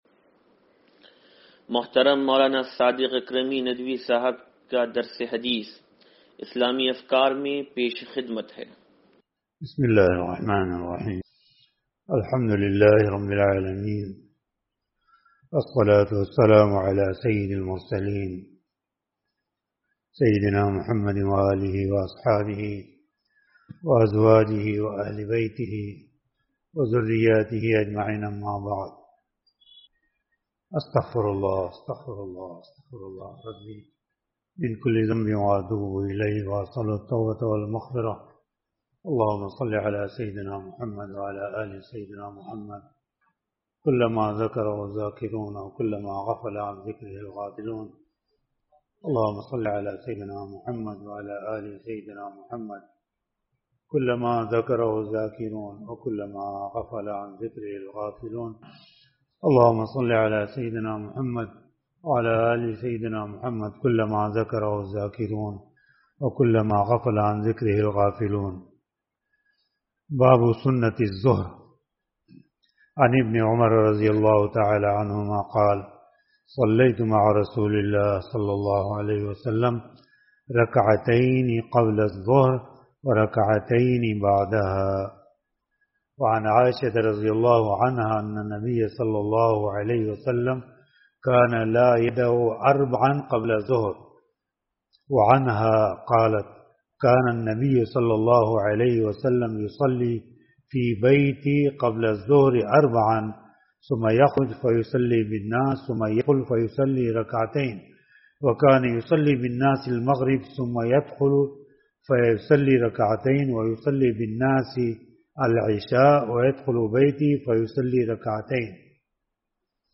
درس حدیث نمبر 0829